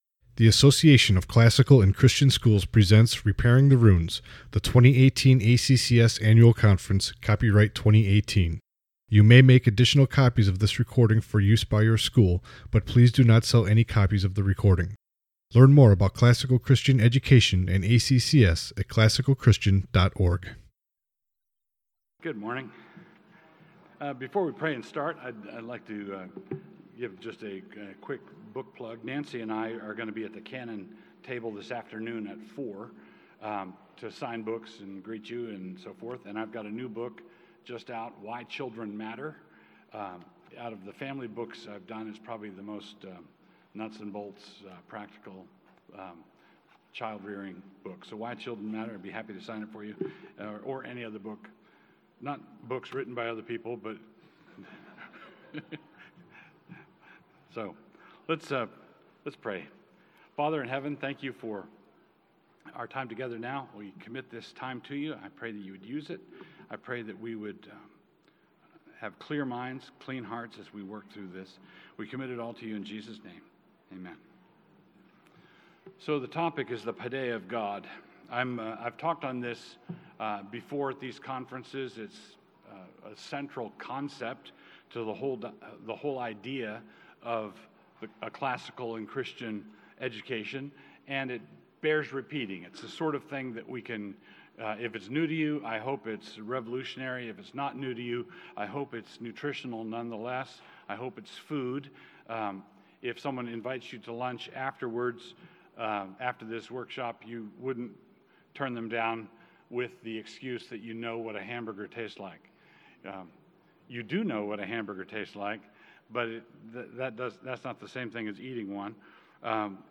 2018 Foundations Talk | 1:01:44 | All Grade Levels, General Classroom, Virtue, Character, Discipline
Additional Materials The Association of Classical & Christian Schools presents Repairing the Ruins, the ACCS annual conference, copyright ACCS.